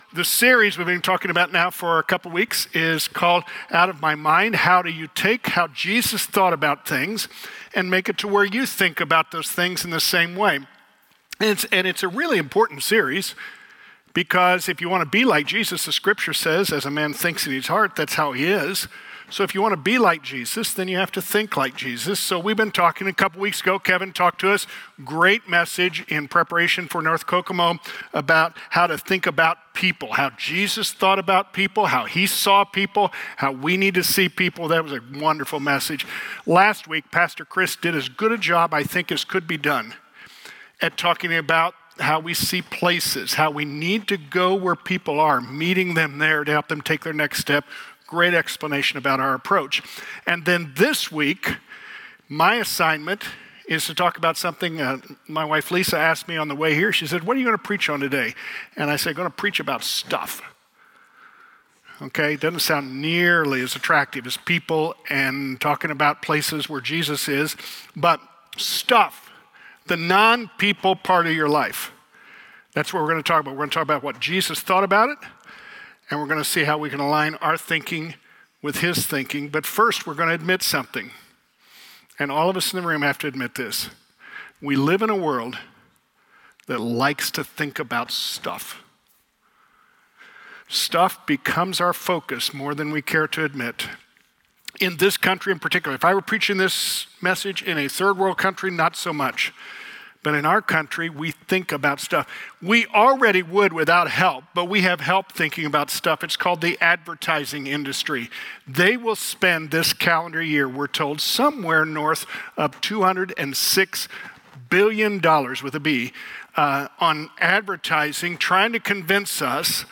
Crossroads Community Church - Audio Sermons